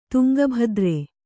♪ tuŋga bhadre